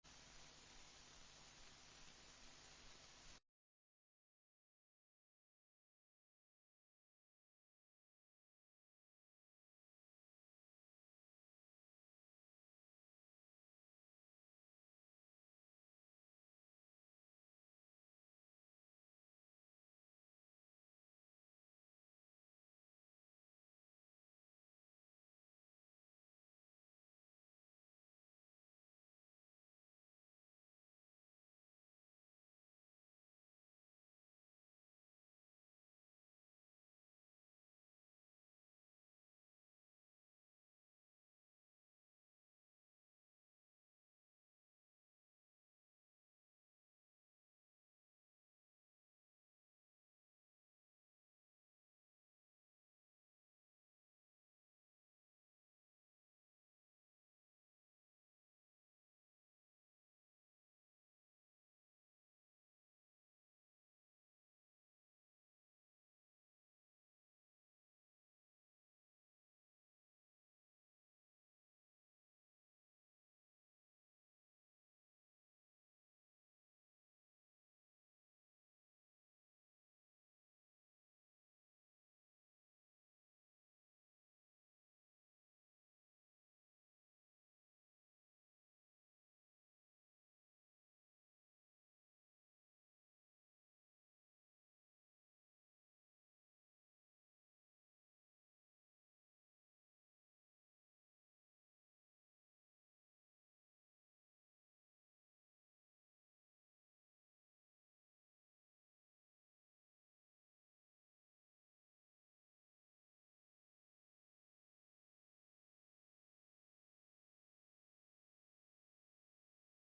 Město Litvínov: 5. zasedání Zastupitelstva města 30.03.2023 7ada176aa46f57e303c343f7352ea6c3 audio